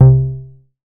MoogAttackUpB.WAV